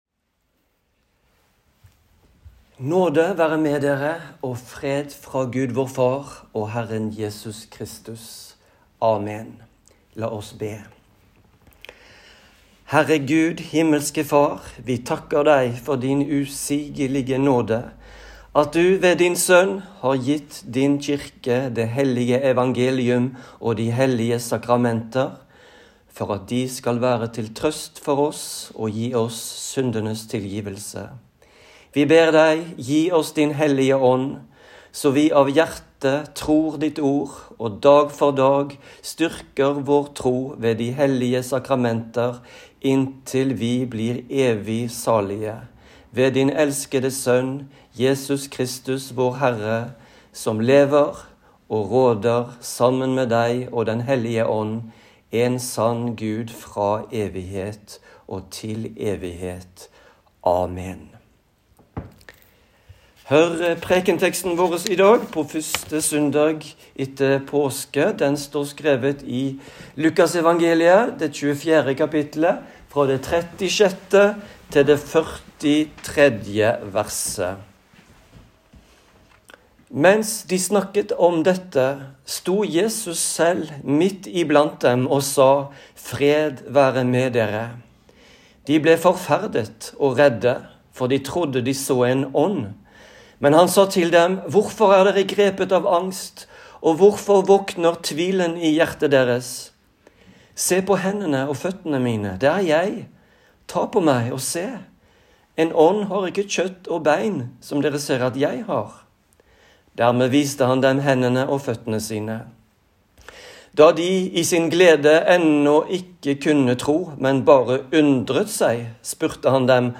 Preken på 1. søndag etter påske